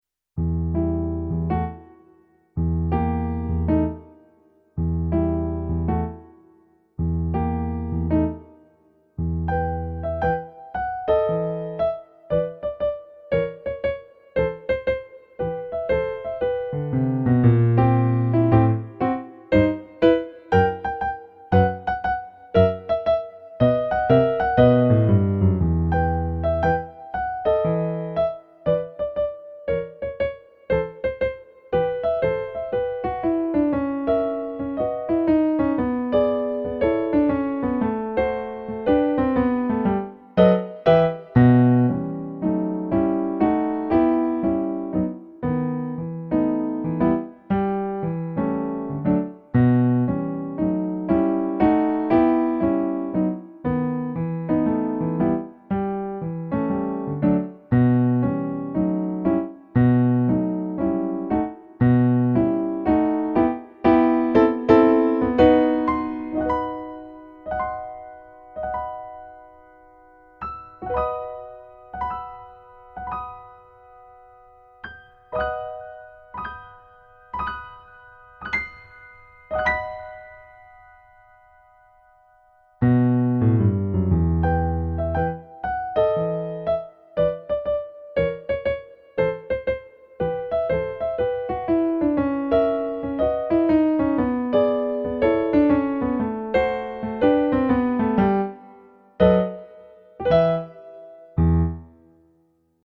swing remix